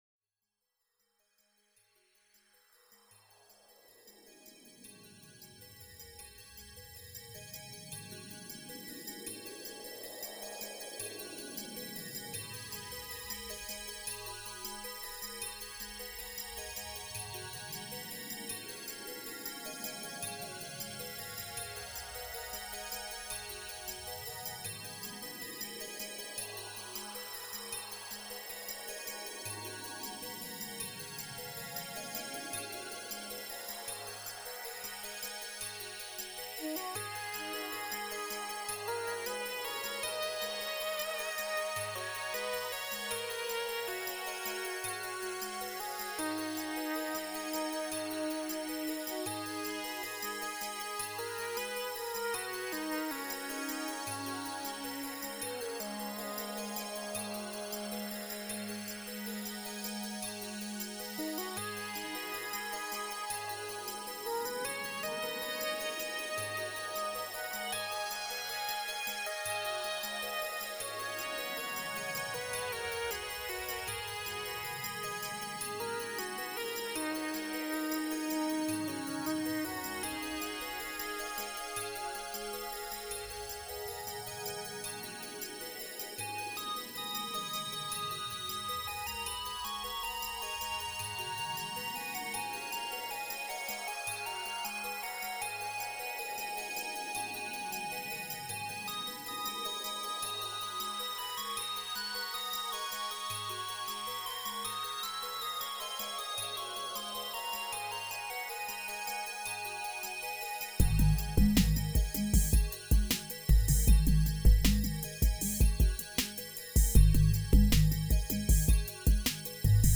JP-8000 på bas och Alesis Ion på lead/melodi: